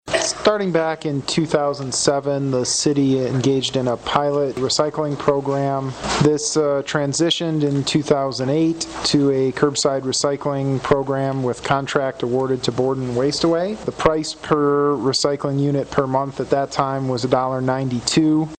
Wednesday night, City Manager Andrew Kuk shared with the City Commission information on the curbside recycling program, starting with how it began.